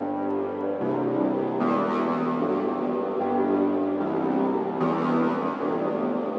诡异的环境陷阱
Tag: 150 bpm Trap Loops Piano Loops 1.08 MB wav Key : G FL Studio